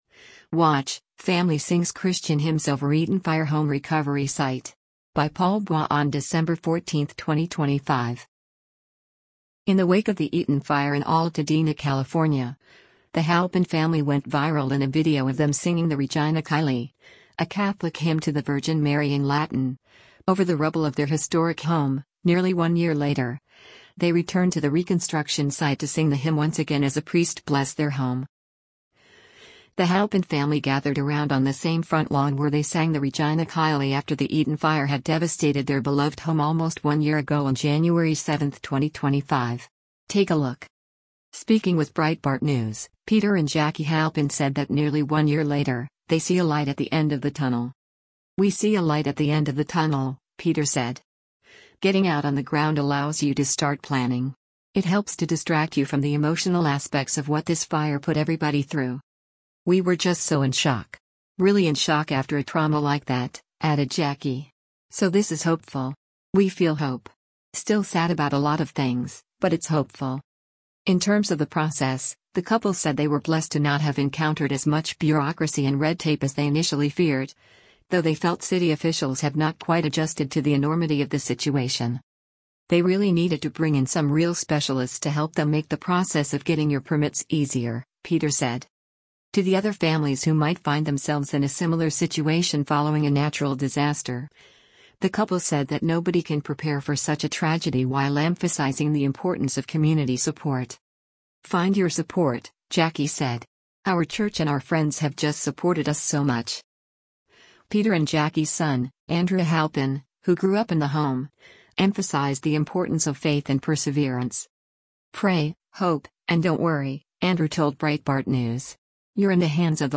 Watch -- Family Sings Christian Hymns over Eaton Fire Recovery Site
Regina Caeli,” a Catholic hymn to the Virgin Mary in Latin